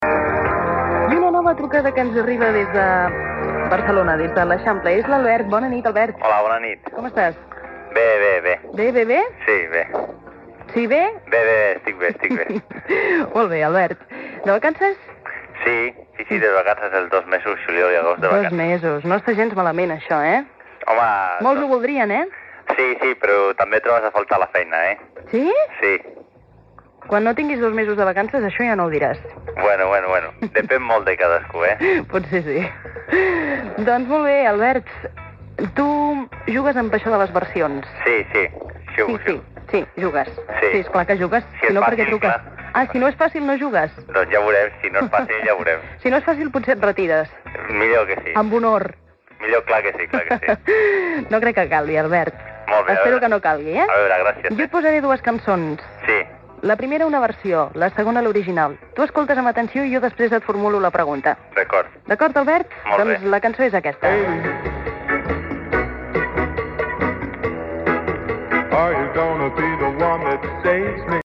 Trucada d'un oient per a participar en un joc del programa.
Musical